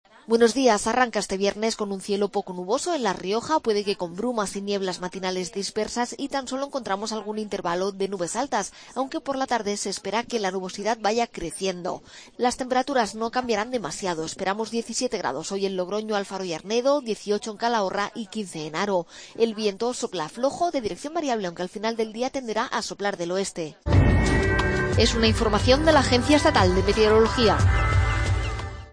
AUDIO: Pronóstico.